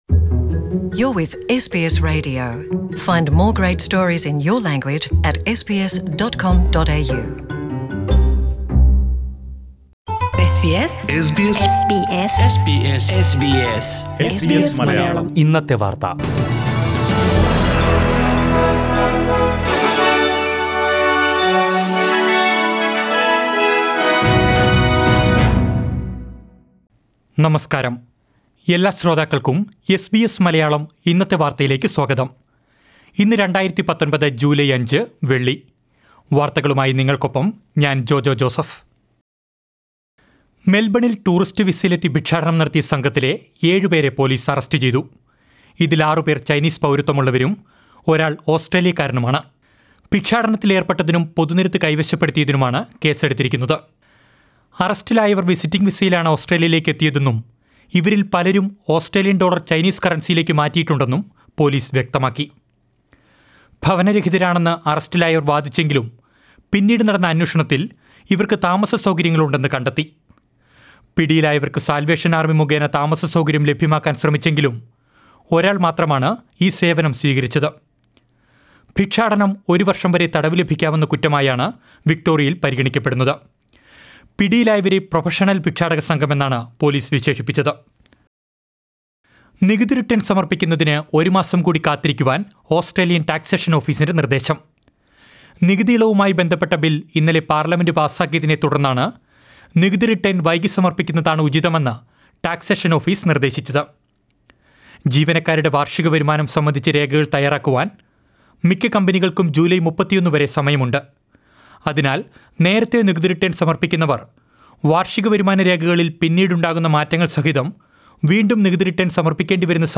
2019 ജൂലൈ അഞ്ചിലെ ഓസ്‌ട്രേലിയയിലെ ഏറ്റവും പ്രധാന വാര്‍ത്തകള്‍ കേള്‍ക്കാം...